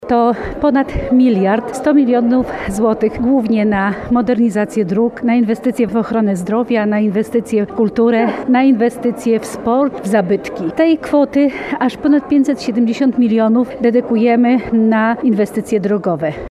– Najwięcej funduszy zostanie przeznaczonych na mazowieckie drogi – mówi wicemarszałek województwa Elżbieta Lanc.